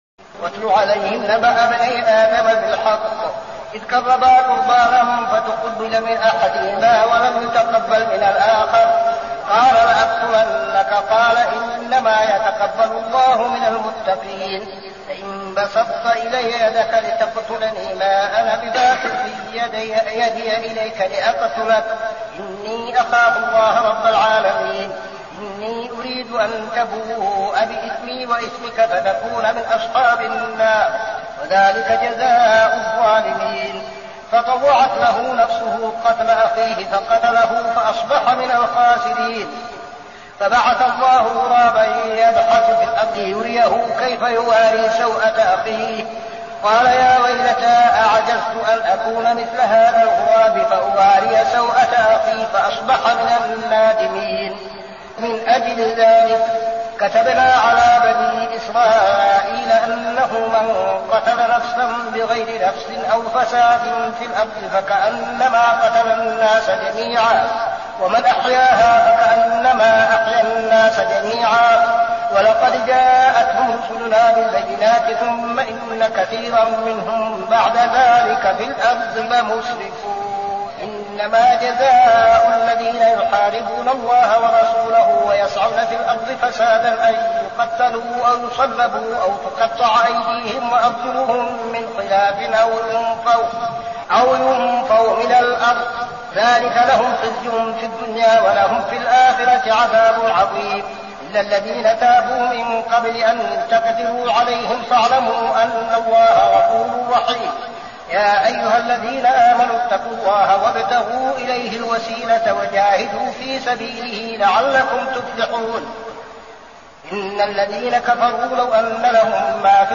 صلاة التراويح ليلة 7-9-1402هـ سورة المائدة 27-82 | Tarawih prayer Surah Al-Ma'idah > تراويح الحرم النبوي عام 1402 🕌 > التراويح - تلاوات الحرمين